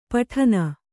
♪ paṭhana